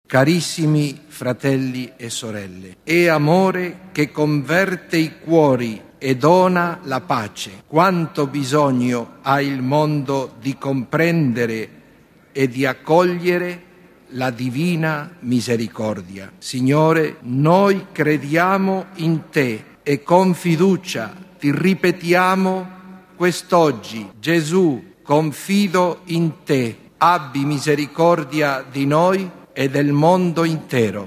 Przed modlitwą Regina Coeli na Placu świętego Piotra arcybiskup Leonardo Sandri odczytał pożegnalny tekst Jana Pawła II, który - jak podkreślił włoski hierarcha - przygotował on tuż przed swą śmiercią na obchodzoną 3 kwietnia Niedzielę Miłosierdzia Bożego.
Czyta Leonardo Sandri